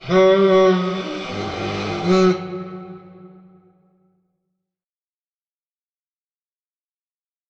Звуки рогов в Майнкрафт
Goat_Horn_Call4.mp3